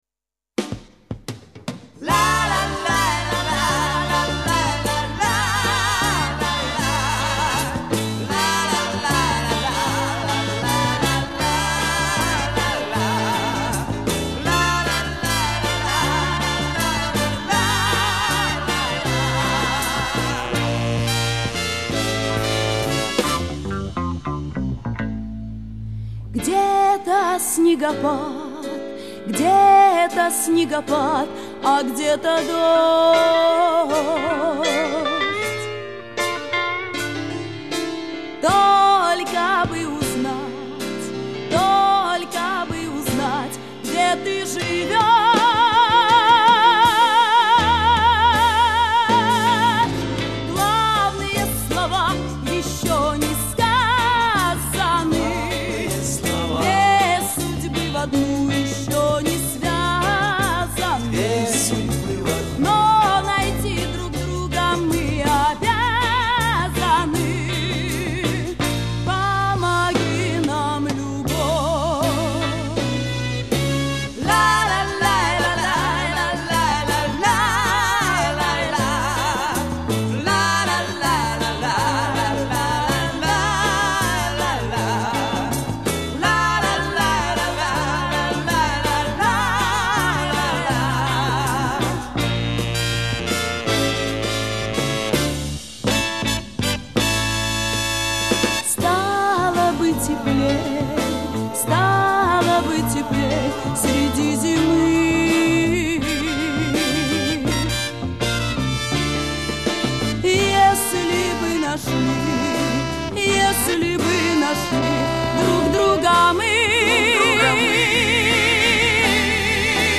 записала вокал